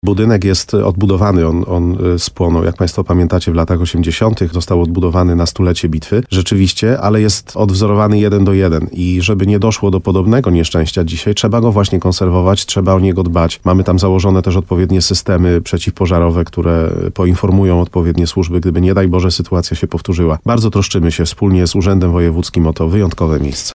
Bardzo troszczymy się z urzędem wojewódzkim o to wyjątkowe miejsce – mówi wójt Mariusz Tarsa .